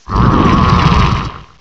pokeemerald / sound / direct_sound_samples / cries / uncomp_carkol.aif